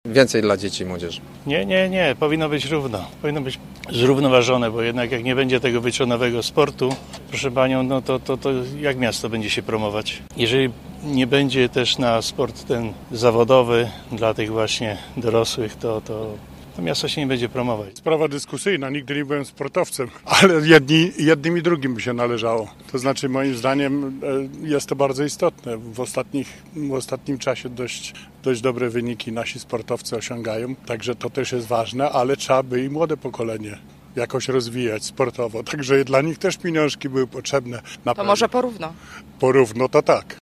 A tak odpowiadaliście dziś na ulicach Gorzowa: